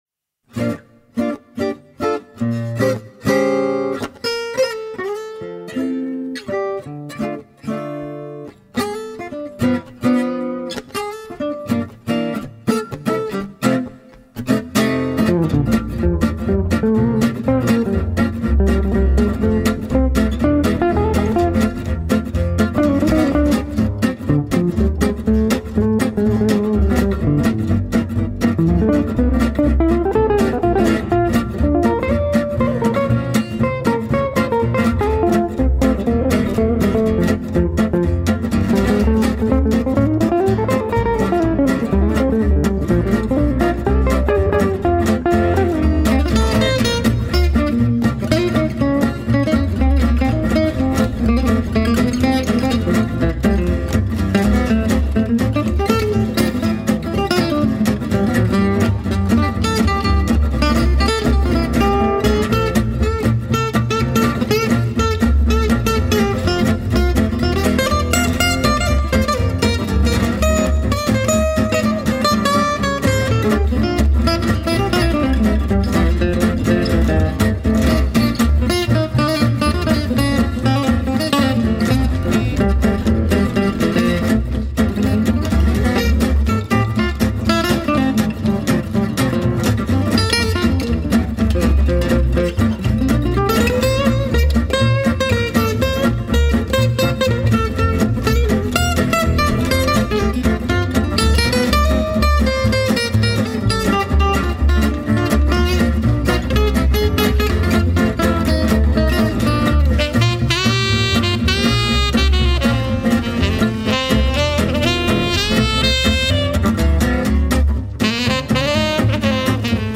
jazz manouche oftewel gipsy jazz
Een gezellig swingende tijd in de studio en veel gelachen.